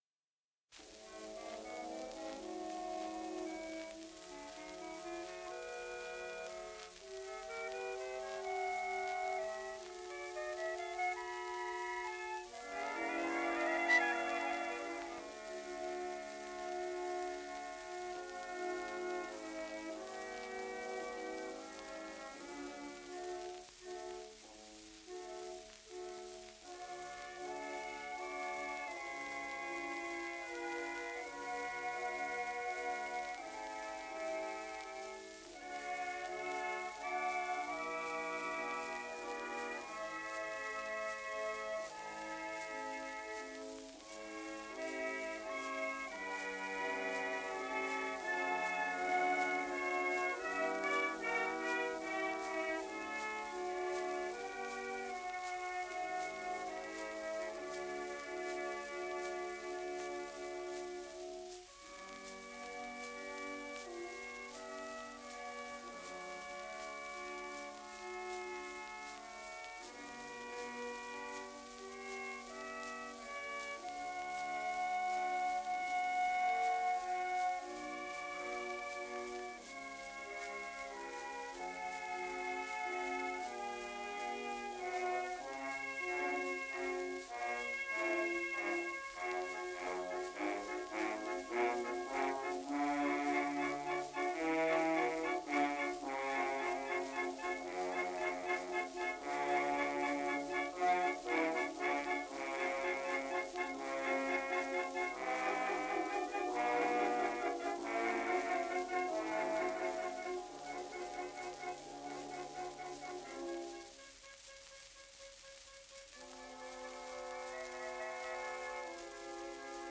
«Умирающий поэт». Оркестр Сузы. Дирижёр А. Прайор.
Запись 1912.